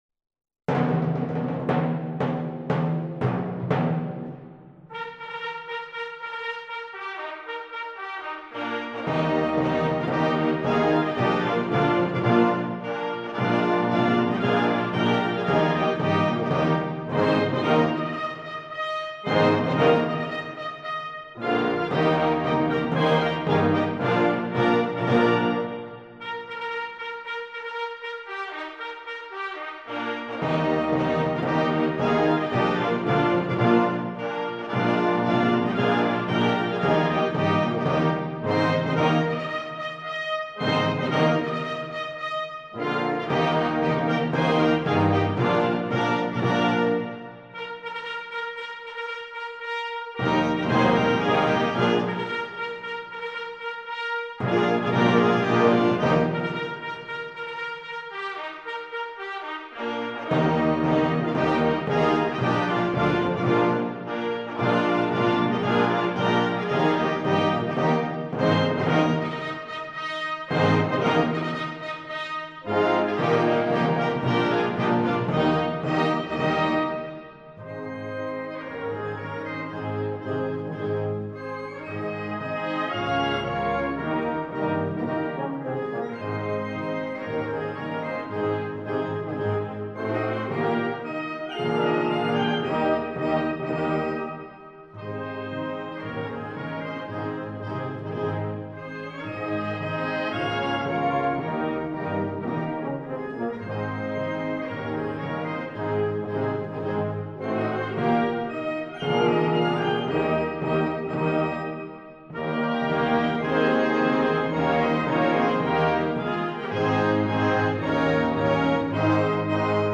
Regimental Slow March: 'Coburg'
30-Slow-March-Coburg.mp3